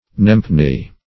Search Result for " nempne" : The Collaborative International Dictionary of English v.0.48: Nempne \Nemp"ne\, v. t. [AS. nemnan to name or call.
nempne.mp3